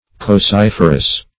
Search Result for " cocciferous" : The Collaborative International Dictionary of English v.0.48: Cocciferous \Coc*cif"er*ous\, a. [L. coccum a berry + -ferous.
cocciferous.mp3